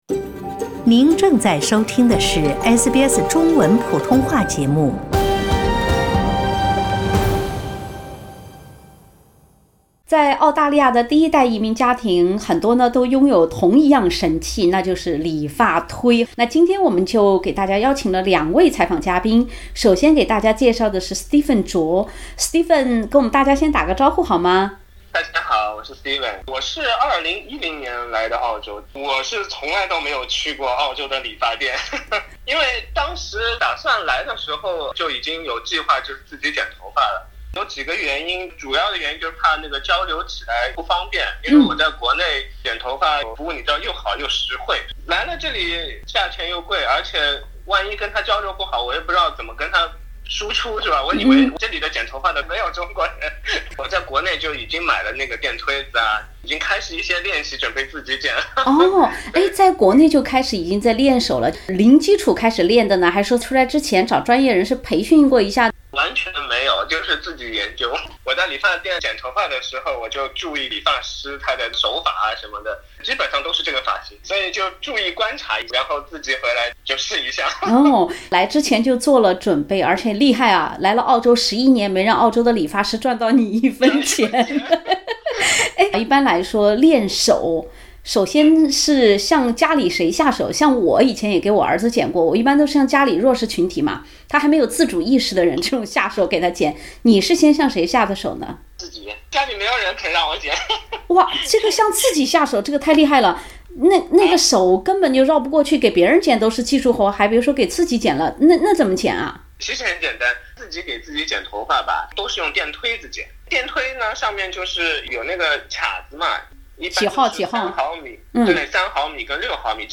下面请收听由本台记者制作的有趣采访。